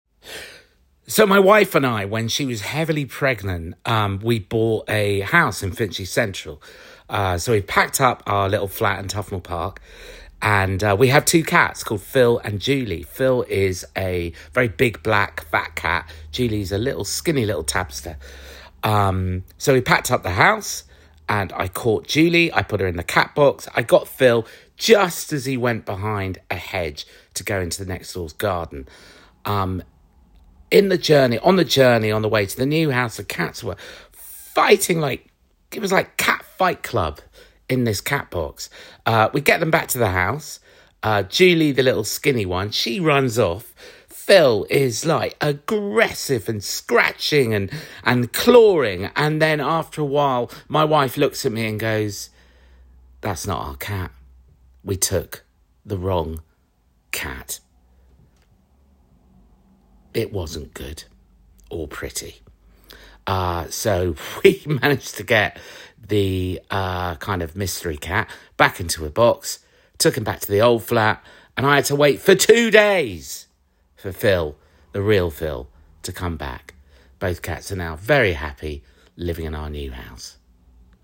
comedy, heightened RP, natural
Gender Male